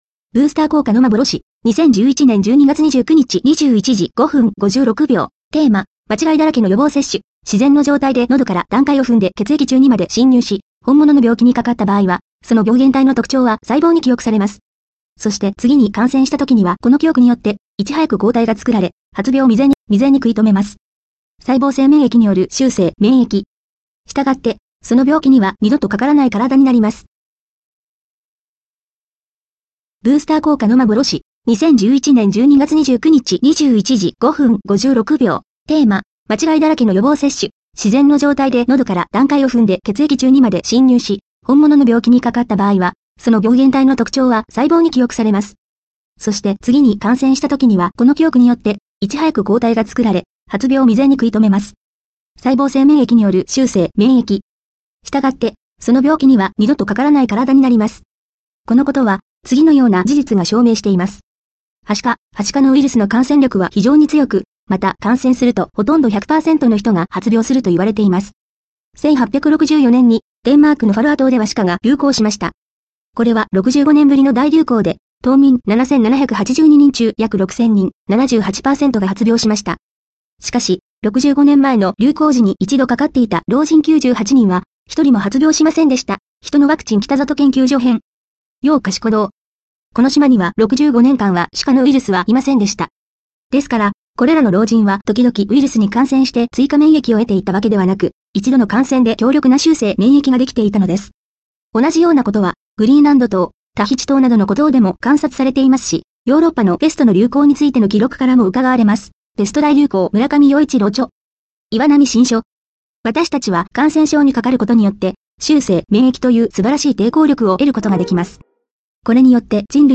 「予防接種をすれば軽く済む」という幻想音声読み上げｍｐ３